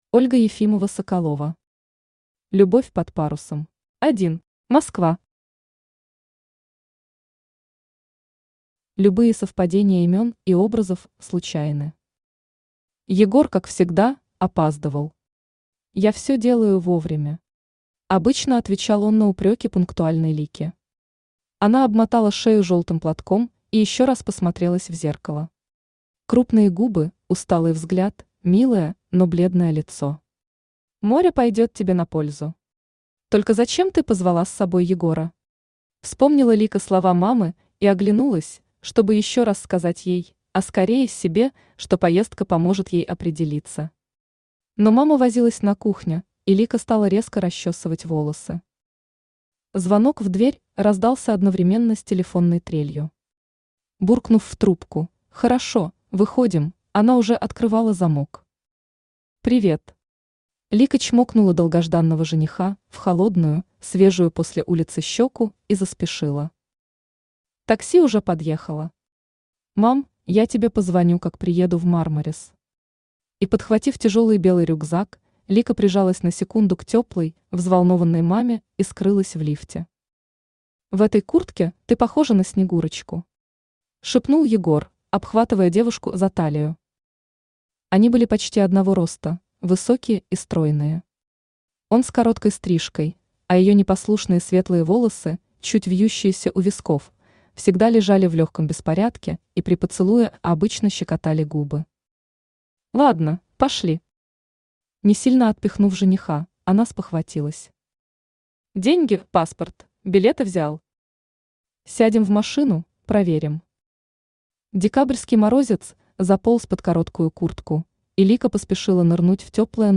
Аудиокнига Любовь под парусом | Библиотека аудиокниг
Aудиокнига Любовь под парусом Автор Ольга Ефимова-Соколова Читает аудиокнигу Авточтец ЛитРес.